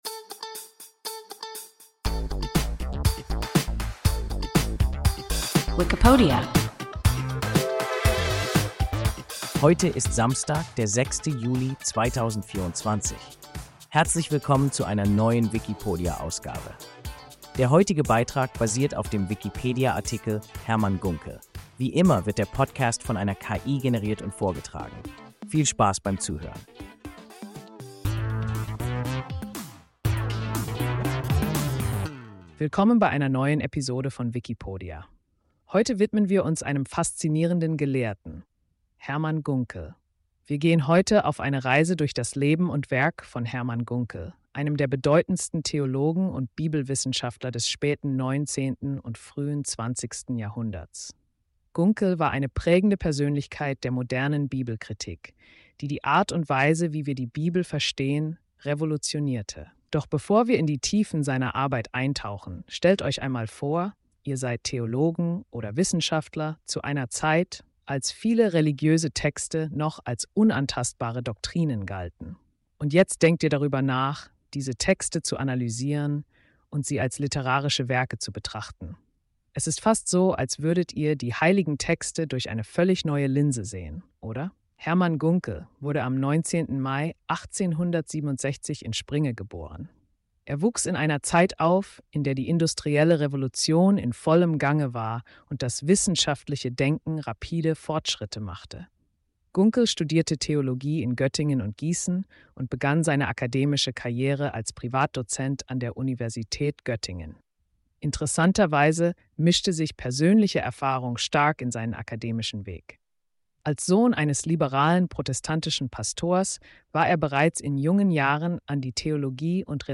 Hermann Gunkel – WIKIPODIA – ein KI Podcast